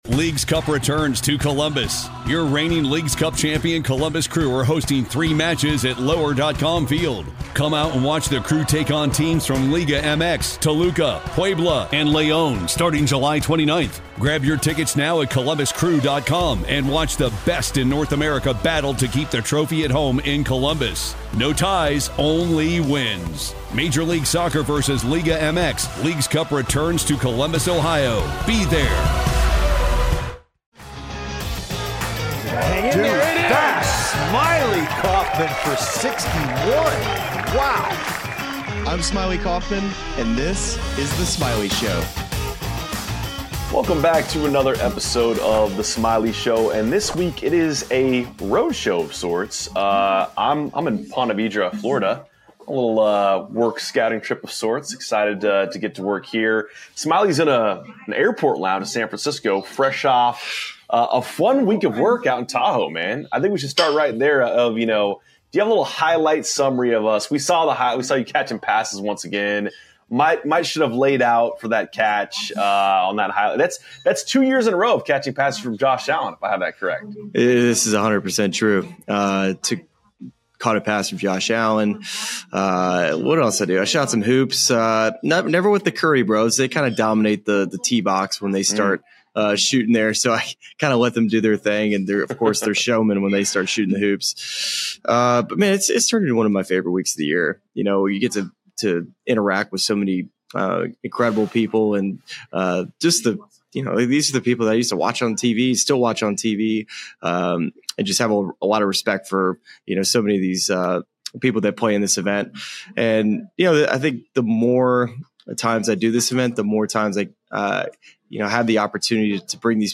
The Smylie Show is on the road this week!